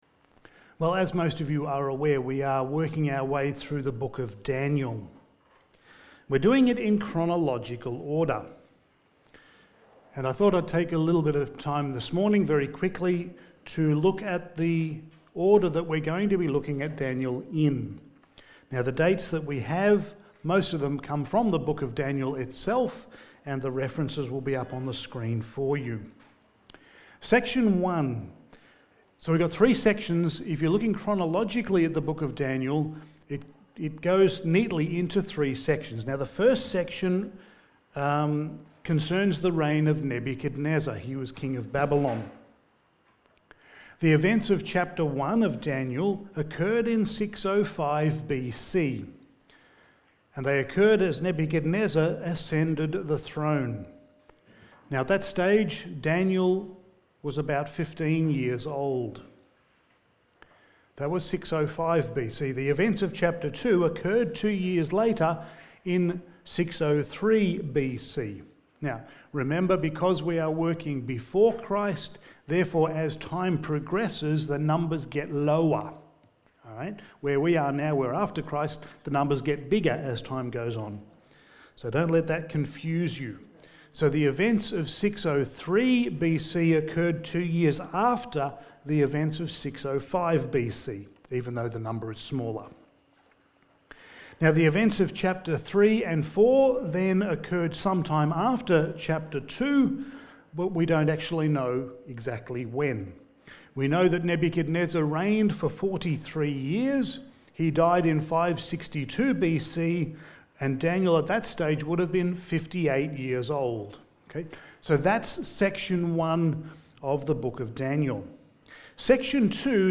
Passage: Daniel 1:8-21 Service Type: Sunday Morning